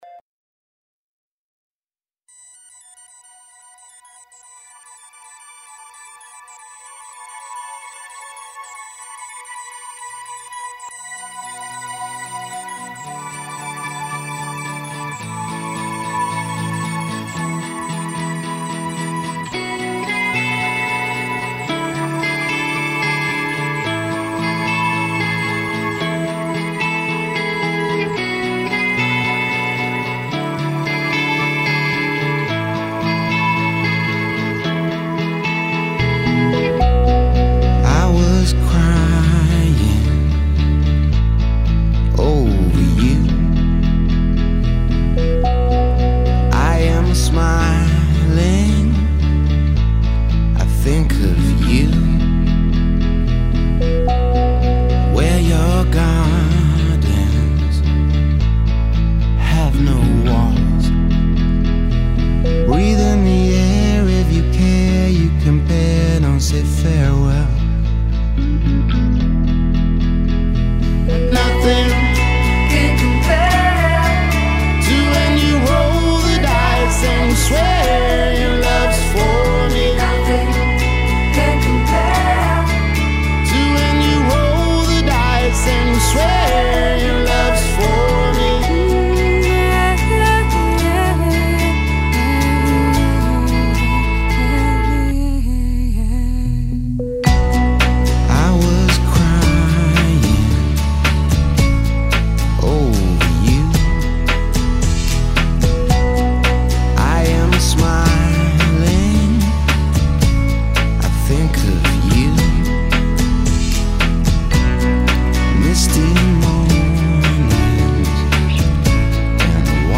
Un viaggio musicale sempre diverso insieme ai nostri tanti bravissimi deejay: nei giorni festivi, qua e là, ogni volta che serve!